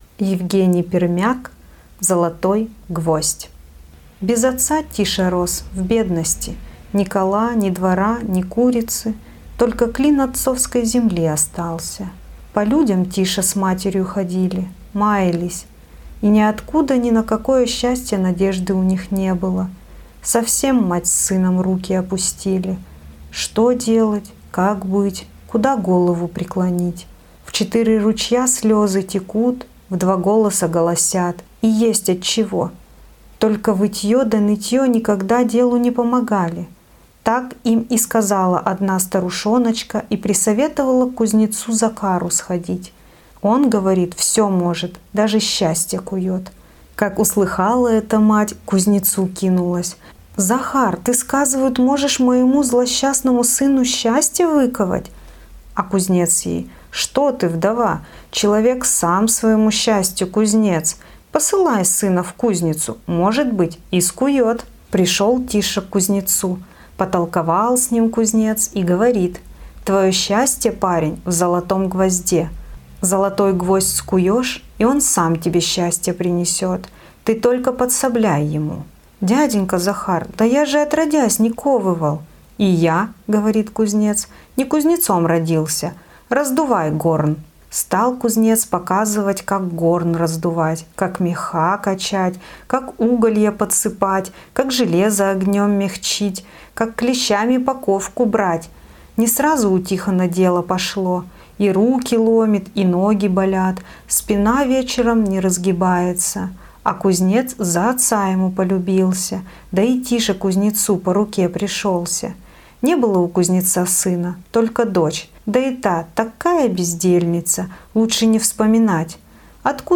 Сказка "Золотой гвоздь" Е.А. Пермяка